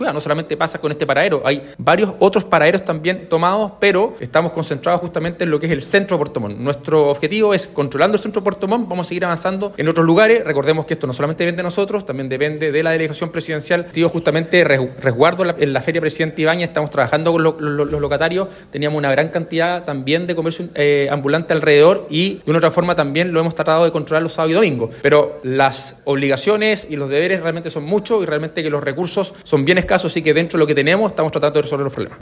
Por su parte, el alcalde de Puerto Montt, Rodrigo Wainraihgt, admitió que esto pasa en varios otros sectores y los recursos para combatir el problema son escasos.